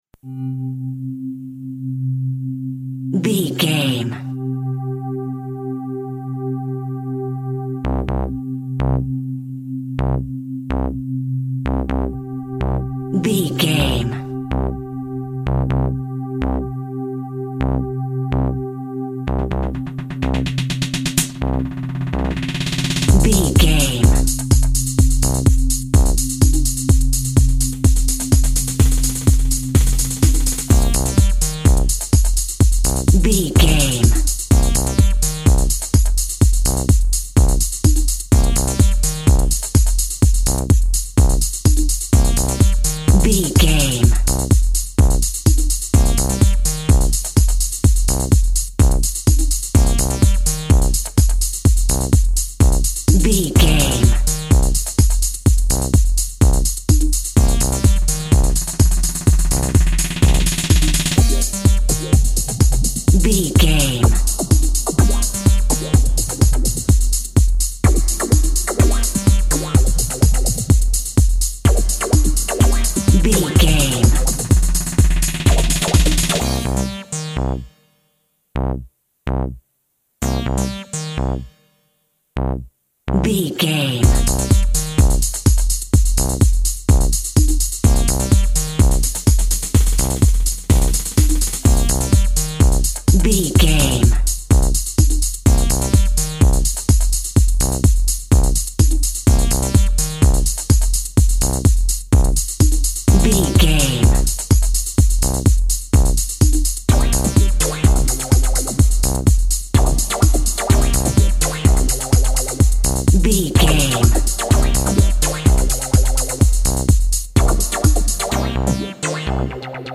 Aeolian/Minor
futuristic
groovy
synthesiser
drum machine
electronic
synth lead
synth bass